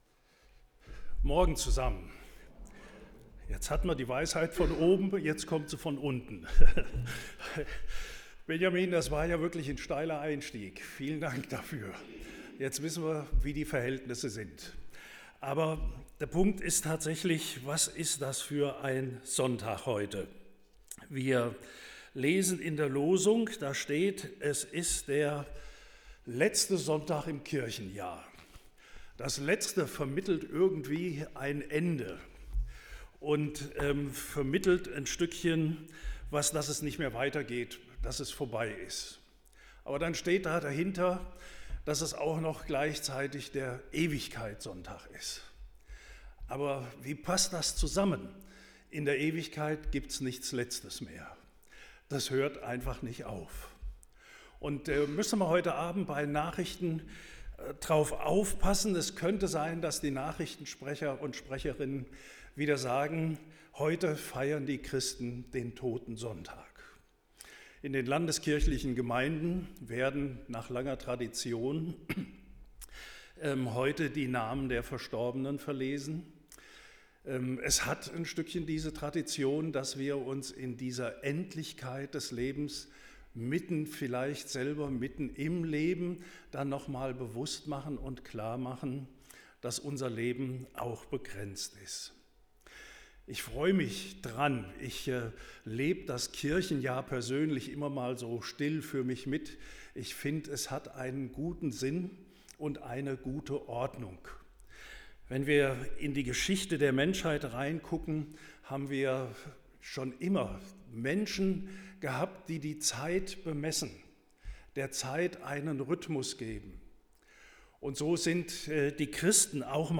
Predigt zum Ewigkeitssonntag